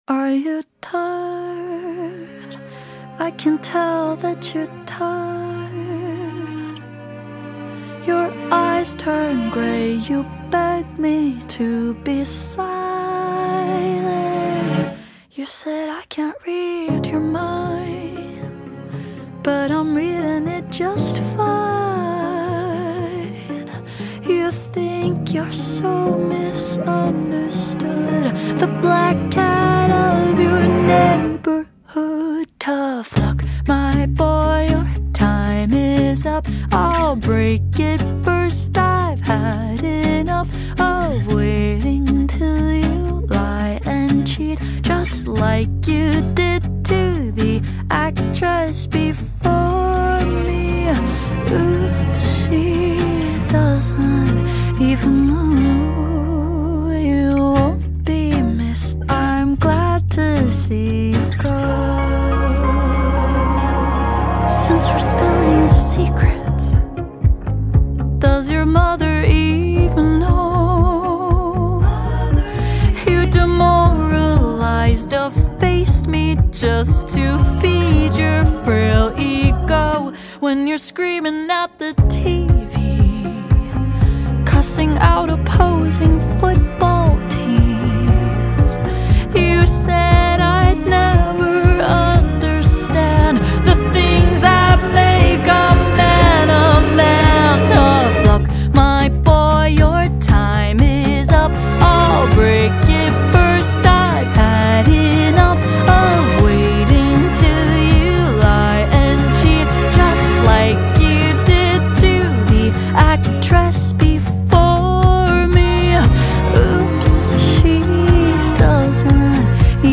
Exceptionally talented singer and songwriter